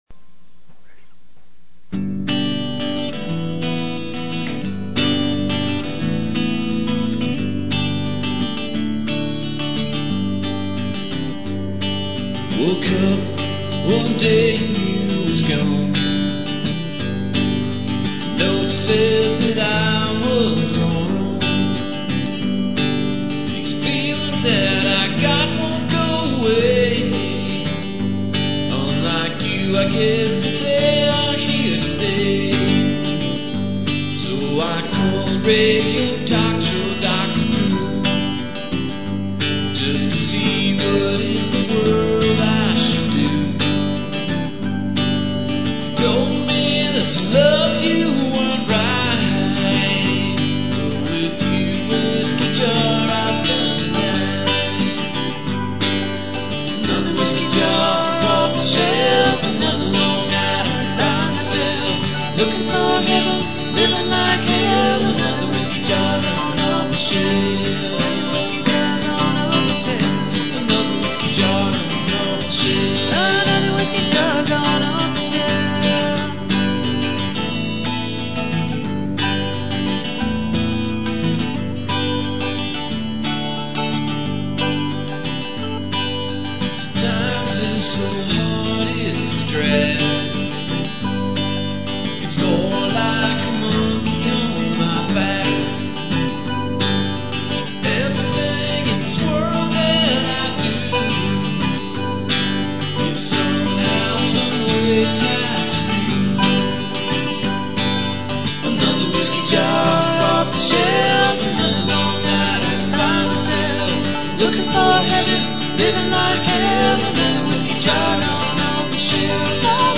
RICH HARMONIES